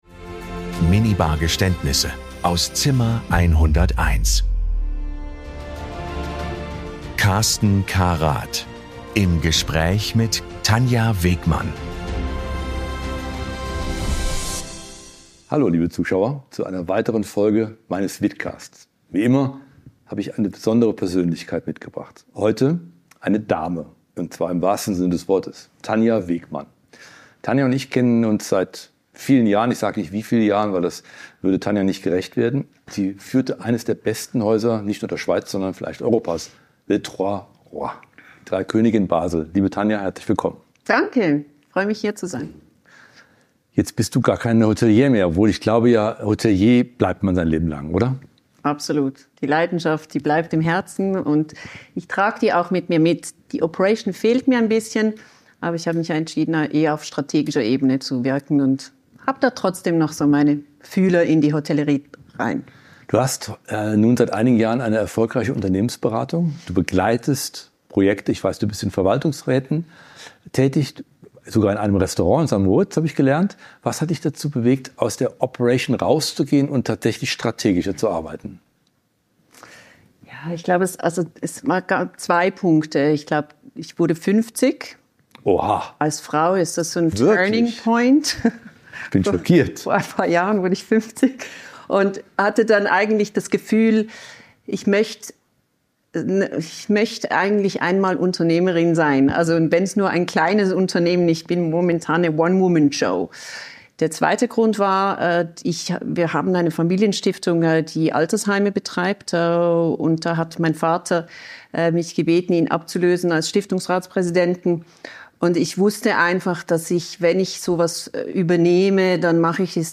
Es wird gelacht, reflektiert und manchmal auch gestanden. Hier wird Hospitality persönlich.